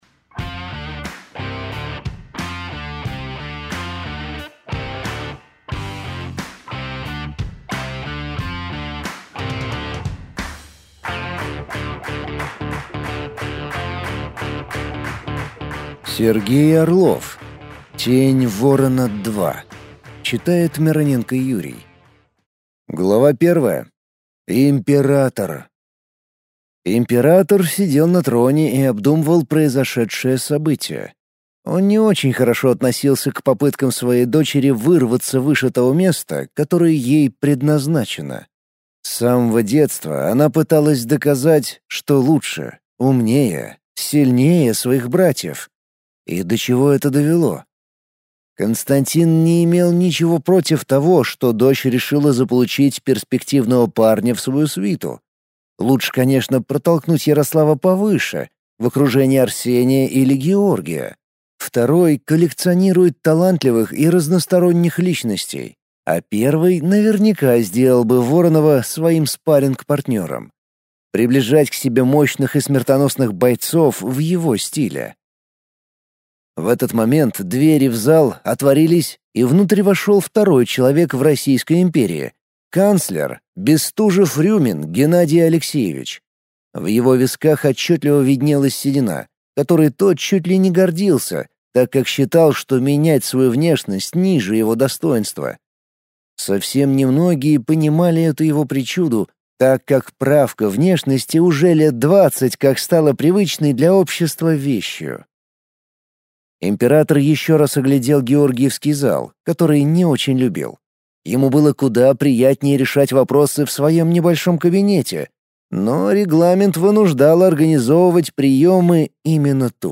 Аудиокнига Тень Ворона – 2 | Библиотека аудиокниг